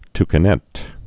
(tkə-nĕt, tkə-nĕt)